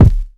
Medicated Kick 7.wav